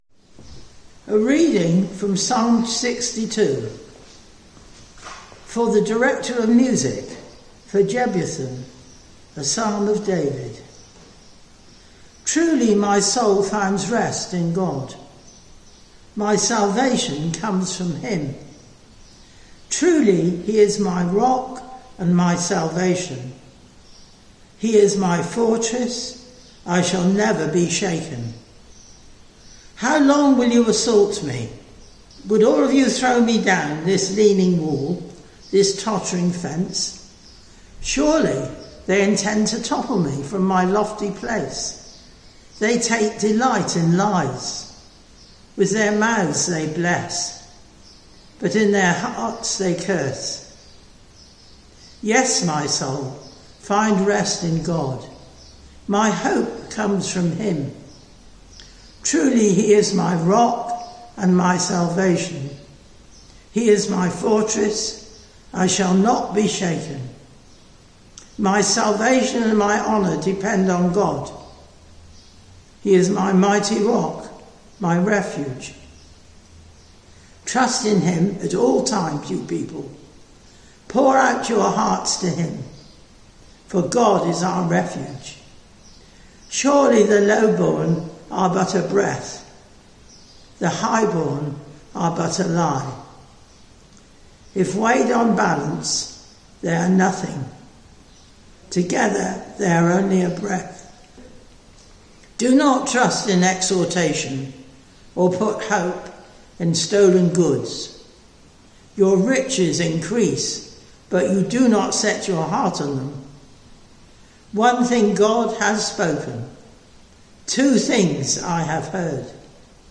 A sermon on Psalm 62